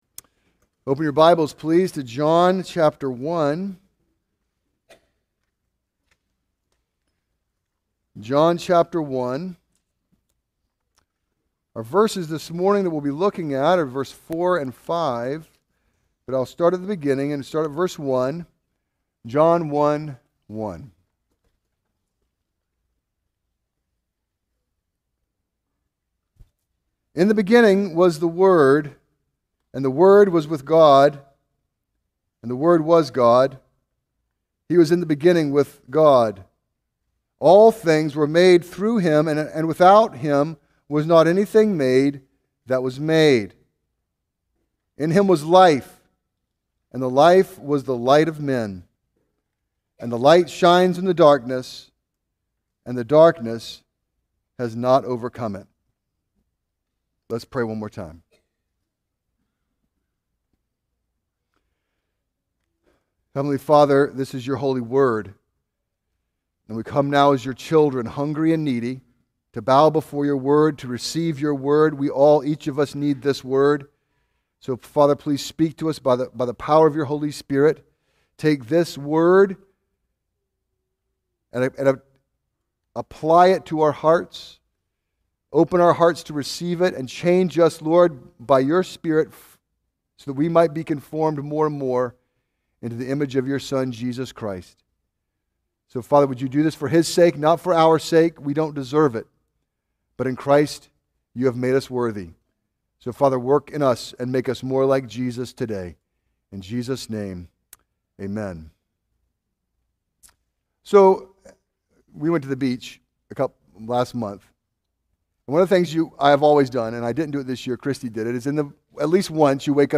A message from the series "Exposition of John."